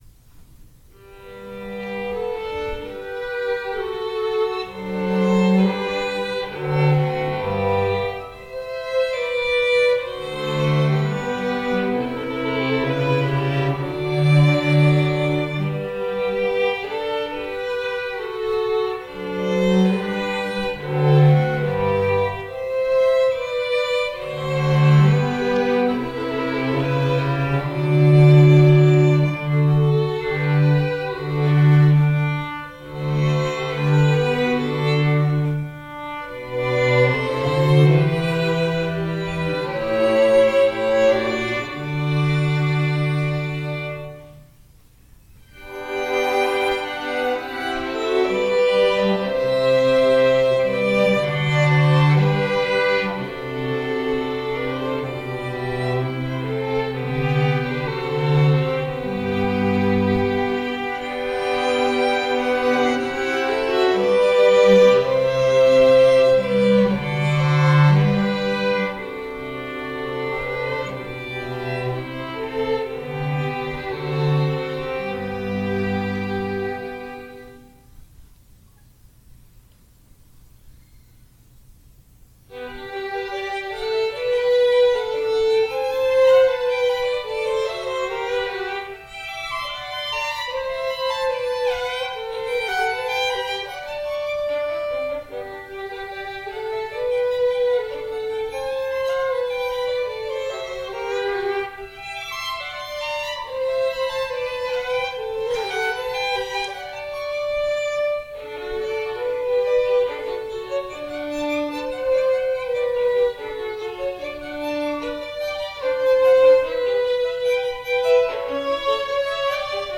Chamber, Choral & Orchestral Music
2:00 PM on August 13, 2017, St. Mary Magdalene
Allegro